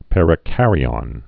(pĕrĭ-kărē-ŏn, -ən)